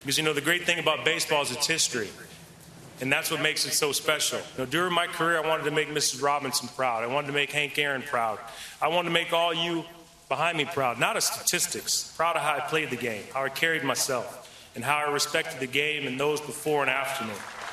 Jeter gave a nod to the legends that came before him in his speech.